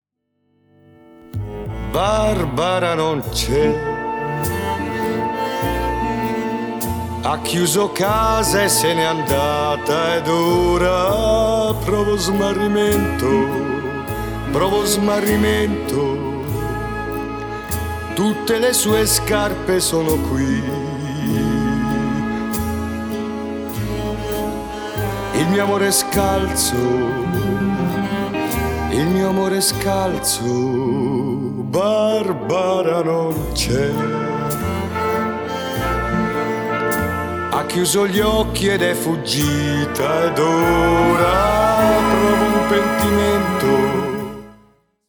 voce solista